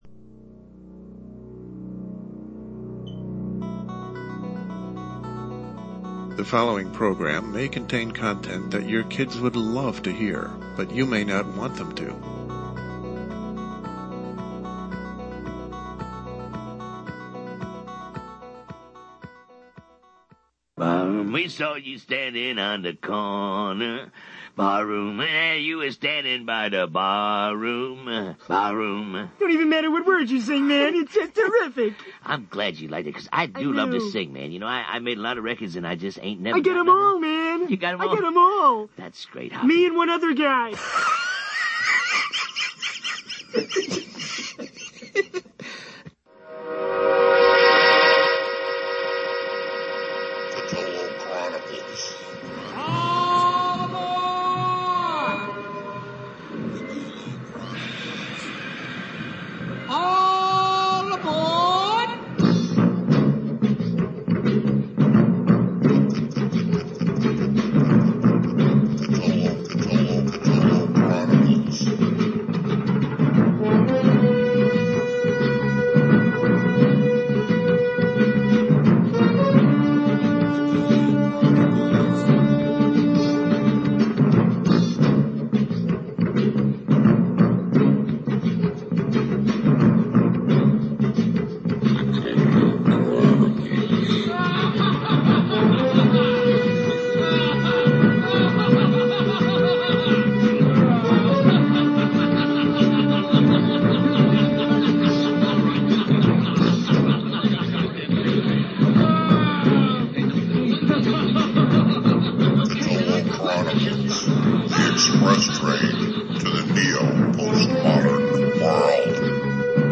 LIVE, Thursday, June 29 at 9 p.m. EST, A mixture of monologues concerning ‘things’ that can kill you, ordinary things we all love to use, things that can make our lives easier and easily end them as well, and much more on a steamy night in some states and states set aflame by Nature’s wrath.